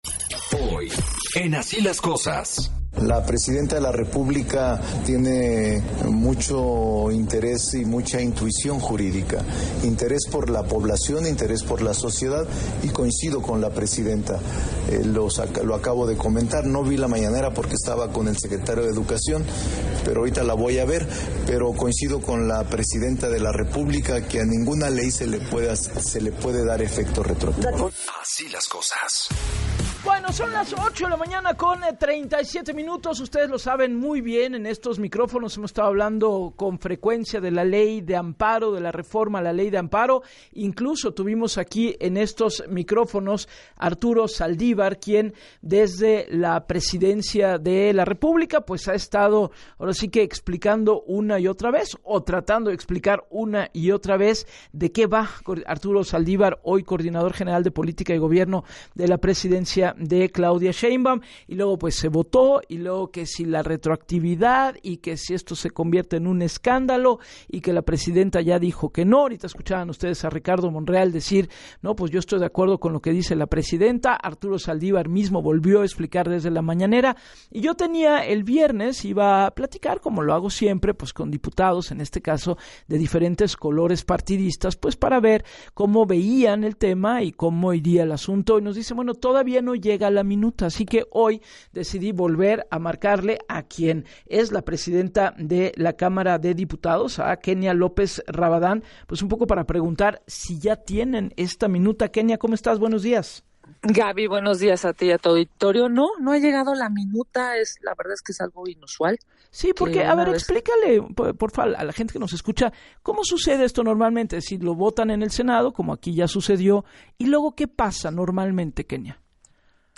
En entrevista con Gabriela Warkentin para Así las Cosas, la legisladora del PAN subrayó que el mandato constitucional establece la obligación de remitir de manera inmediata las minutas aprobadas.